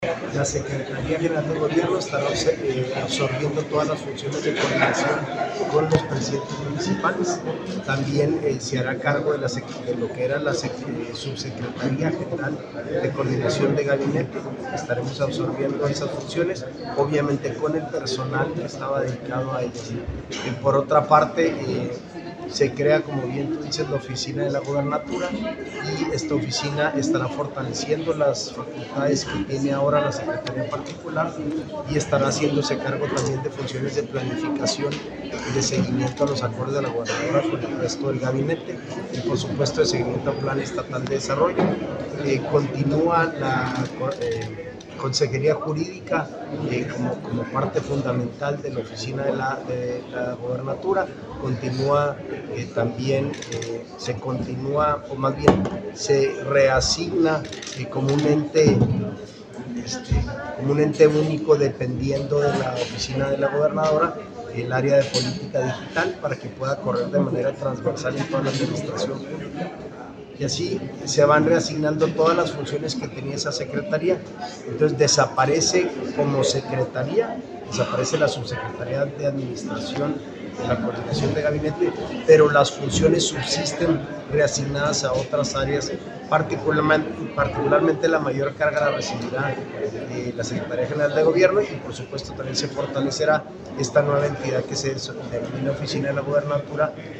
AUDIO: SANTIAGO DE LA PEÑA GRAJEDA, TITULAR DE LA SECRETARÍA GENERAL DE GOBIERNO